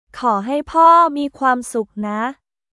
コー・ハイ・ポー・ミー・クワームスック・ナ